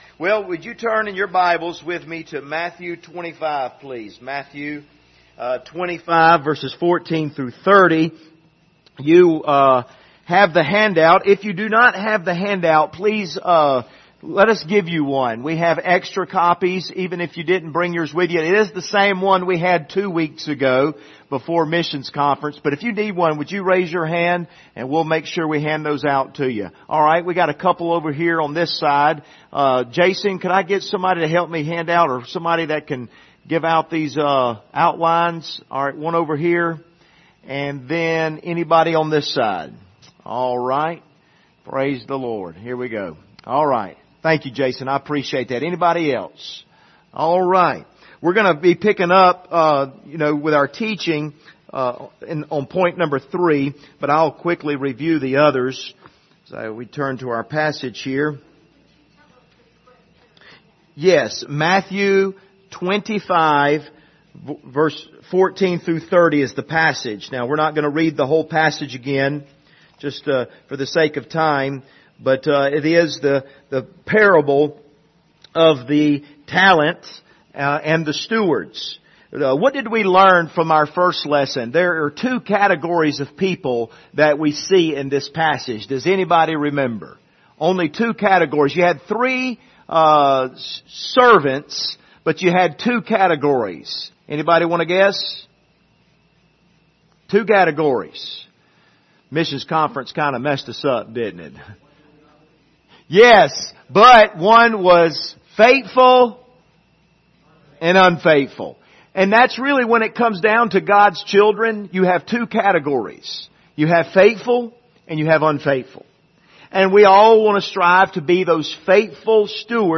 Passage: Matthew 25:14-30 Service Type: Wednesday Evening Topics